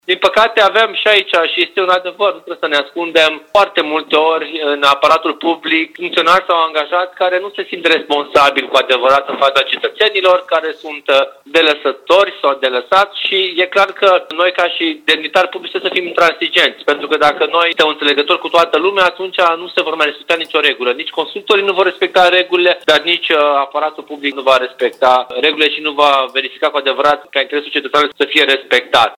Viceprimarul Ruben Lațcău reamintește că timișorenii au posibilitatea să reclame neregulile pe platforma de sesizări a primăriei Timișoara. Edilul recunoaște, însă, că problemele sesizate nu sunt rezolvate întotdeauna cum ar trebui.